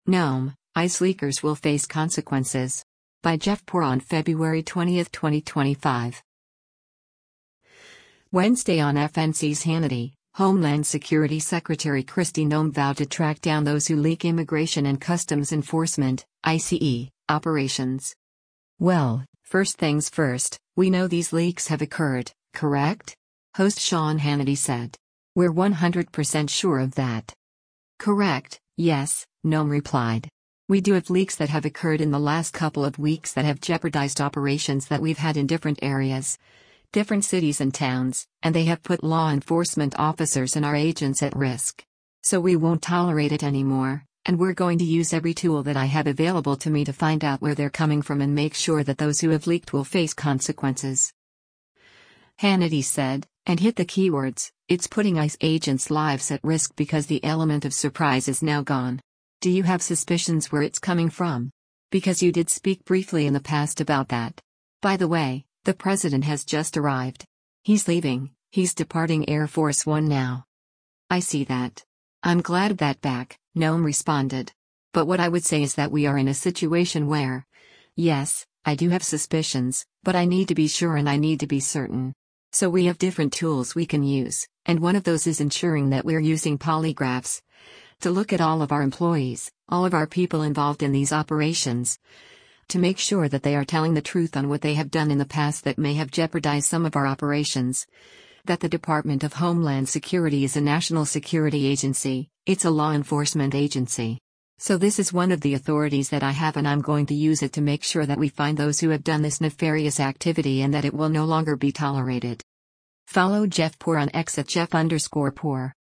Wednesday on FNC’s “Hannity,” Homeland Security Secretary Kristi Noem vowed to track down those who leak Immigration and Customs Enforcement (ICE) operations.